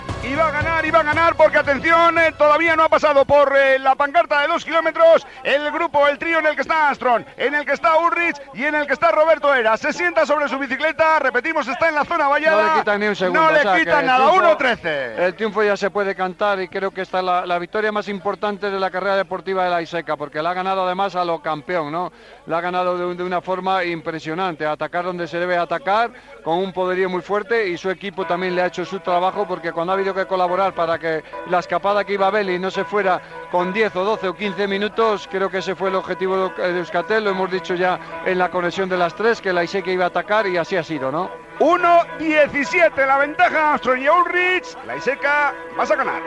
Narració del final de l'etapa del Tour de França entre Tarbes i Luz Ardidenen, en la qual guanya el ciclista basc Roberto Laiseka
Esportiu